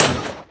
minecraft / sounds / mob / blaze / hit3.ogg